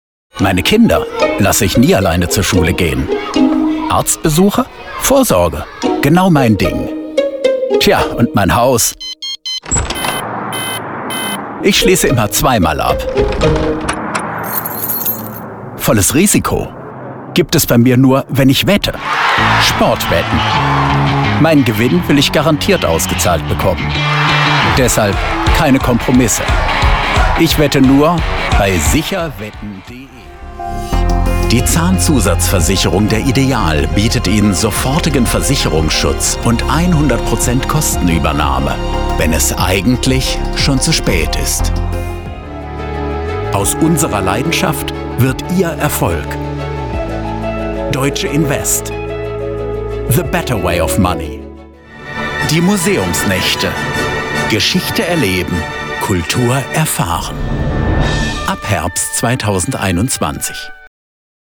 Deutscher Sprecher, Bariton, Stimme für Trickfilm, Cartoon, Animation, Videogame, Trailer, Werbung
Kein Dialekt
Sprechprobe: Werbung (Muttersprache):